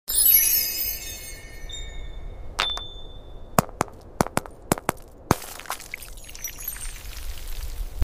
A close up of a large, sound effects free download
A small silver hammer strikes it with a clean “ping” that resonates like a wind chime. The crack spreads with a slow “crrrrk” sound, ice crystals flaking off in delicate layers. Inside is a swirling, liquid-blue core that makes a soft, flowing “whoosh” as it spills out and evaporates into mist.